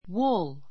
wúl ウ る （ ⦣ × ウ ー る ではない）